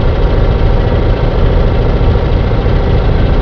Engine3
ENGINE3.WAV